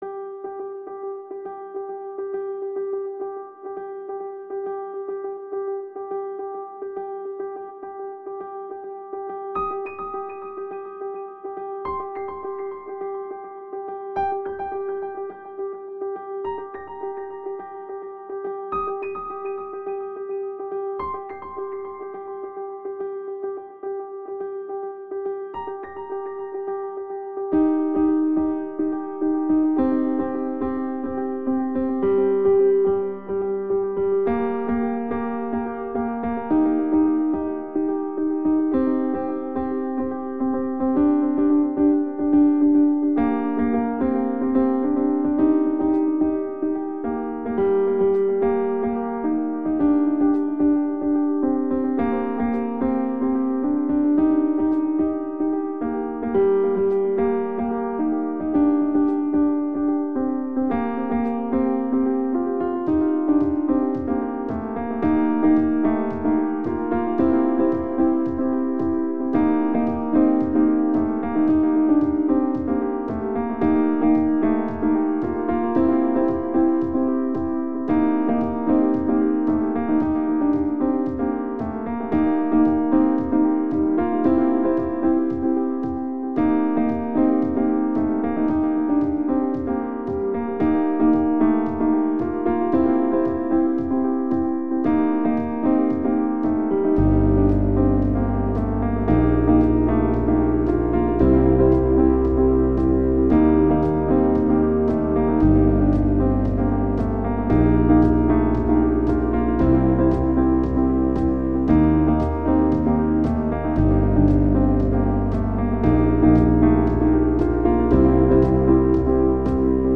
Tags: Piano, Guitar, Percussion